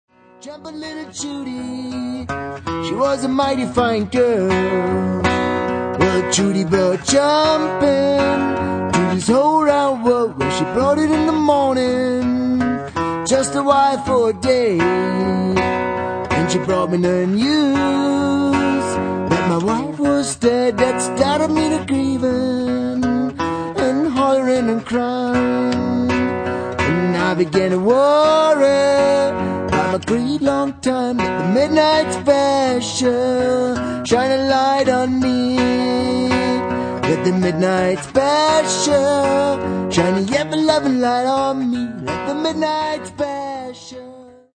Blues-Klassiker